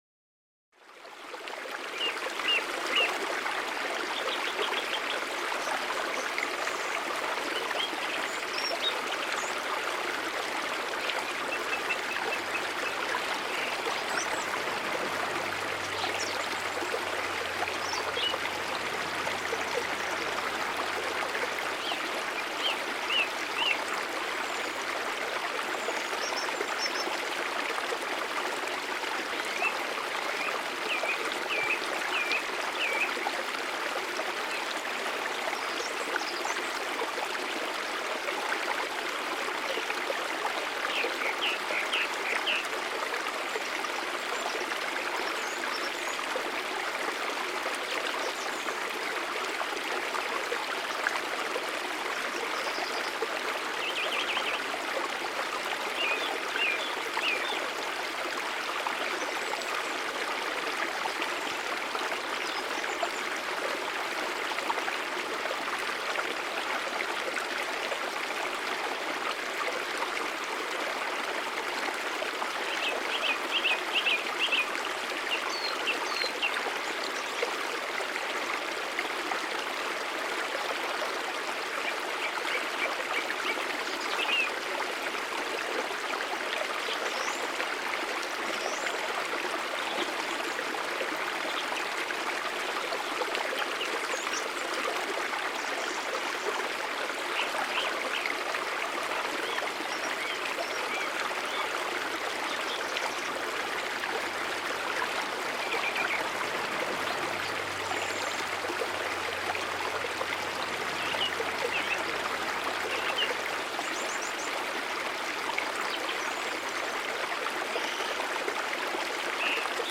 GEIST-BERUHIGUNG: Bachlauf-Beruhigung mit entspannendem Waldplätschern